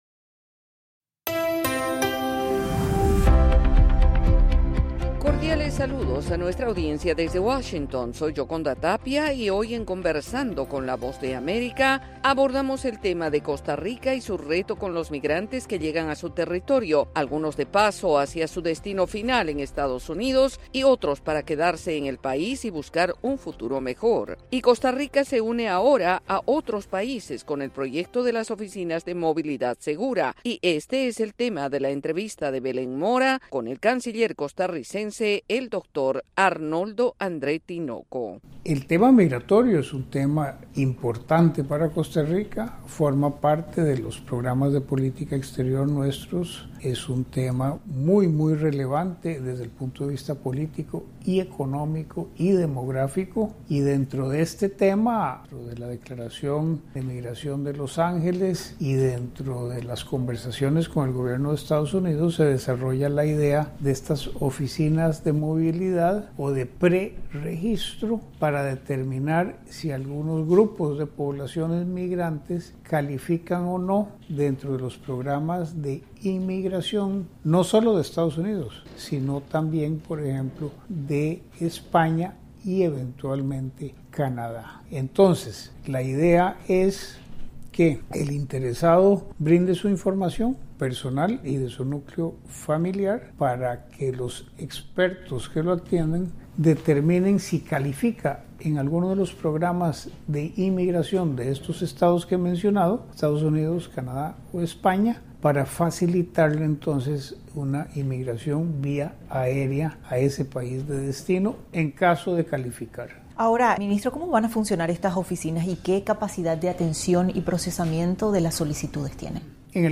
Conversamos con el canciller de Costa Rica, Arnoldo André Tinoco, ofreciendo detalles sobre el trabajo del gobierno para atender a los migrantes y el trabajo de las Oficinas de Movilidad Segura.